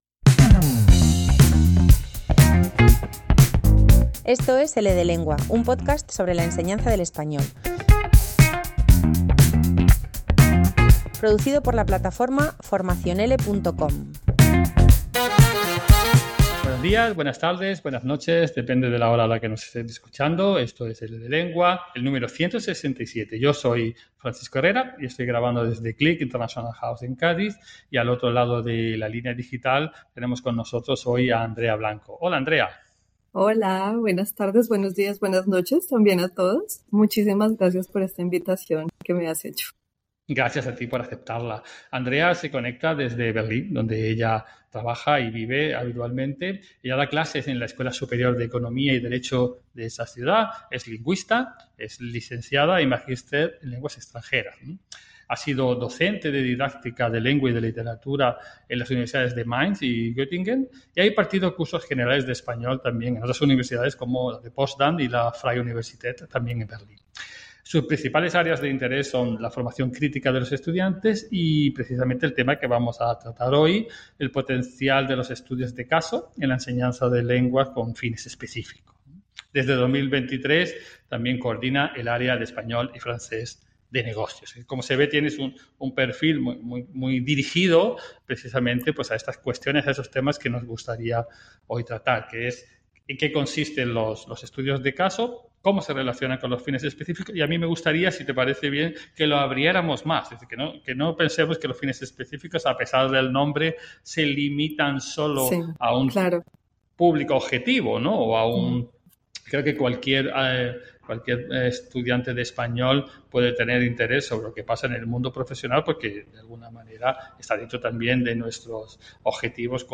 Una charla